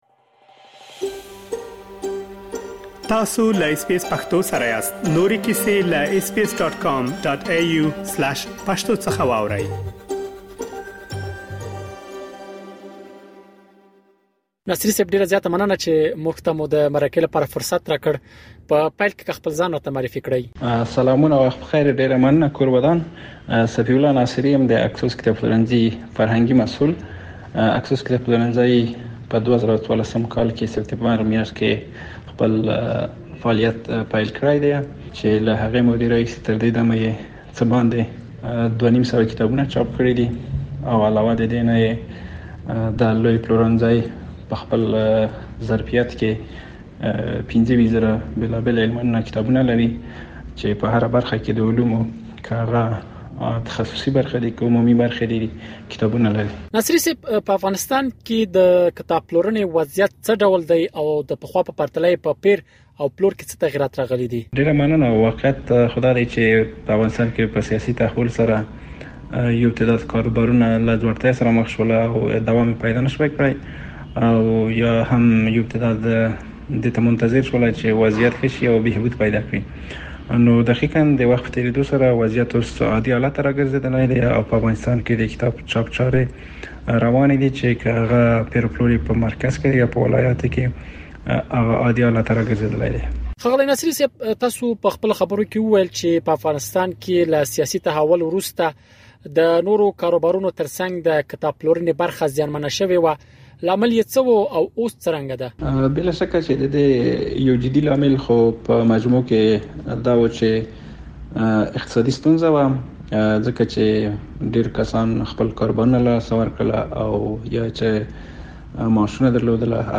په افغانستان کې د کتاب پلورنې وضعیت څه ډول دی او د پخوا په پرتله يې په پېر او پلور کې څه تغیرات راغلي دي؟ اس بي اس پښتو په کابل کې د کتابونو د پېر او پلور په اړه له یوه کتاب پلورونکي سره مرکه ترسره کړې.